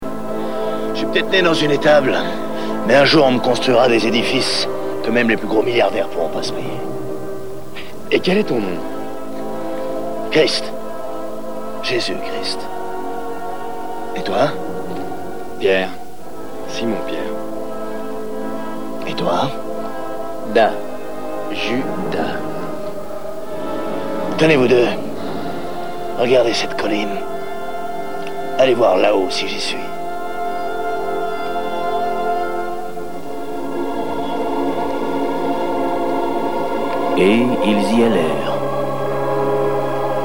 Reconaissez-vous la musique de fond?